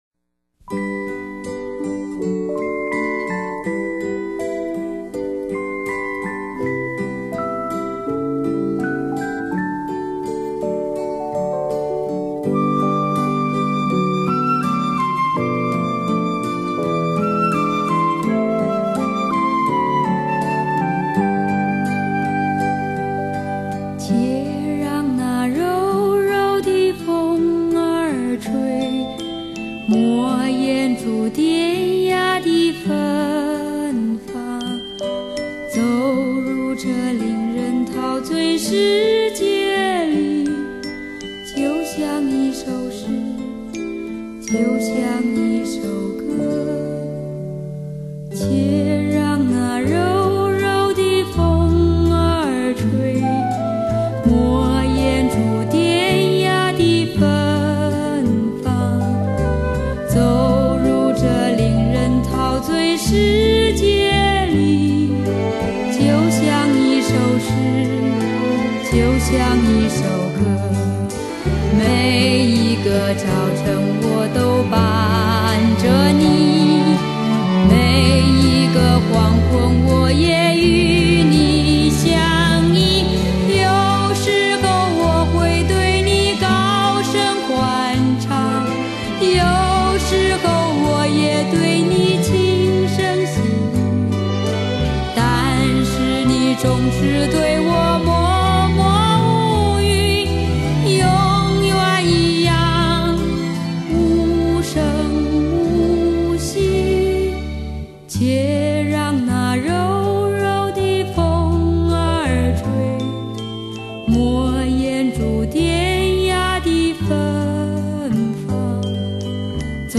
正宗絕對原聲原唱!
校園民歌運動已滿30週年，在您記憶的行囊中，還遺留有多少的青春音韻？